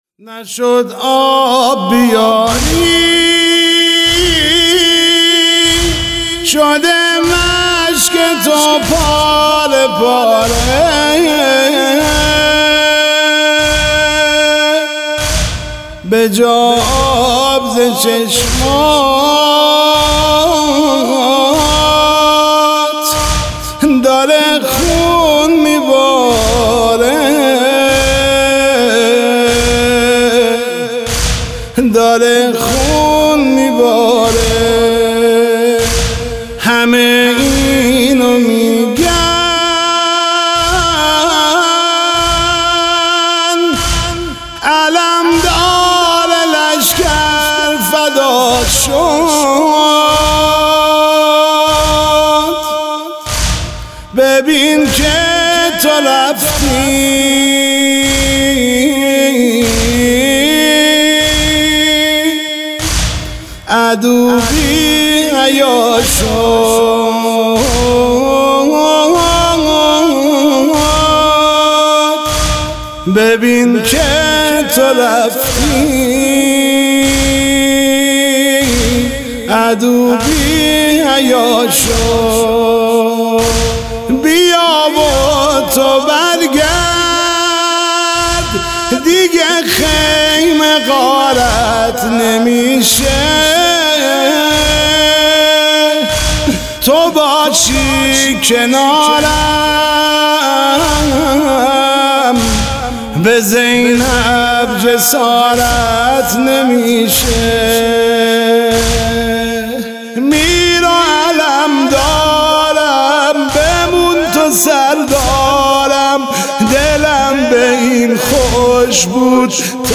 نوحه نشد آب بیاری شده مشک تو پاره پاره